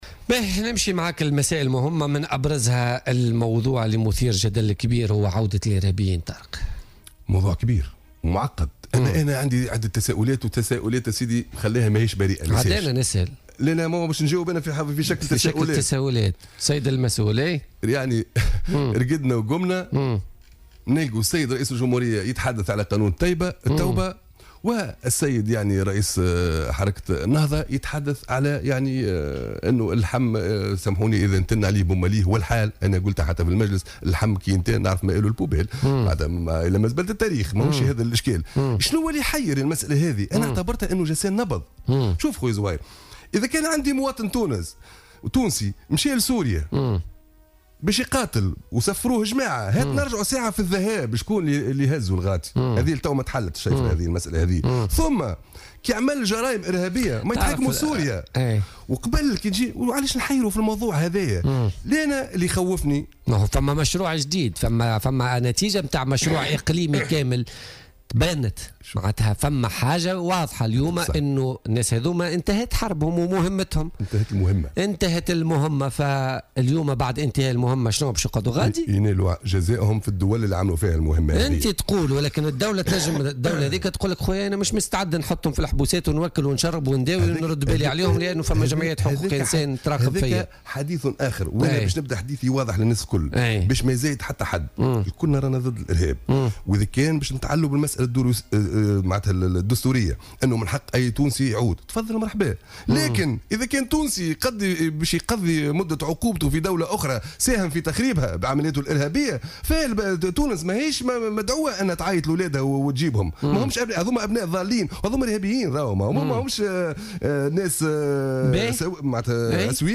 ينكب نواب بالبرلمان من مختلف الكتل البرلمانية، على إعداد مبادرة تشريعية ضد عودة الإرهابيين إلى أرض الوطن، وفق ما صرح به النائب عن حزب الاتحاد الوطني الحر بمجلس نواب الشعب طارق الفتيتي خلال استضافته اليوم الخميس في برنامج "بوليتكا".